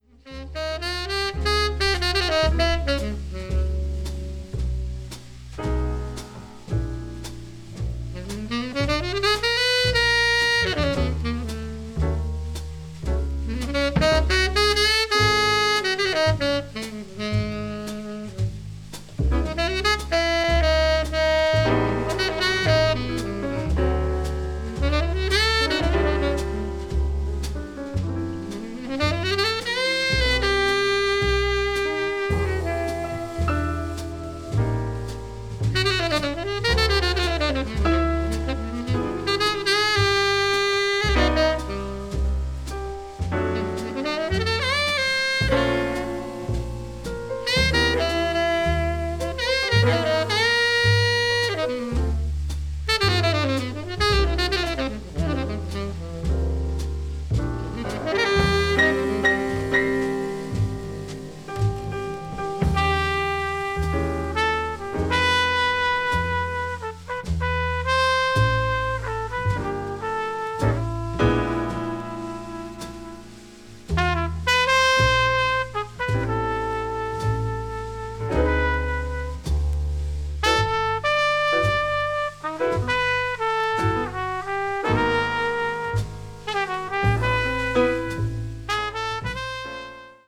media : EX+/EX+(わずかにチリノイズが入る箇所あり)
modal jazz   modern jazz   post bop